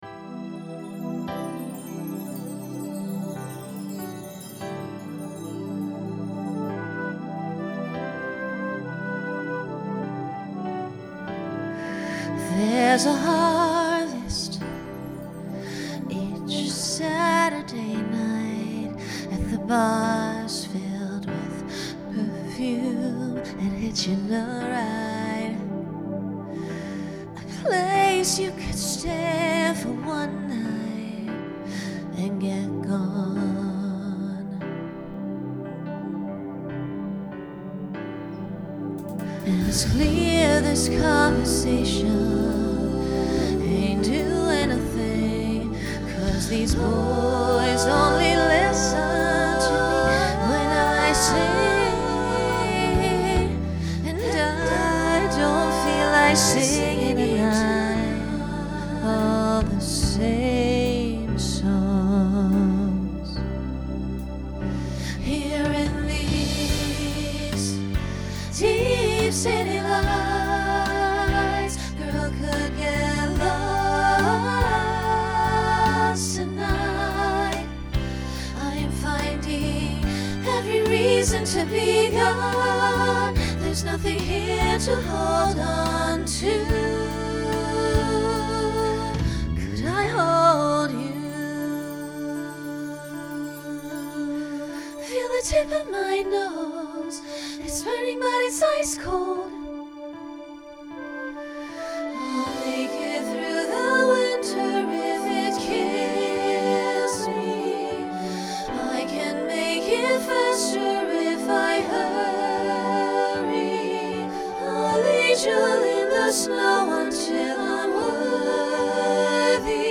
Genre Pop/Dance
Ballad Voicing SSA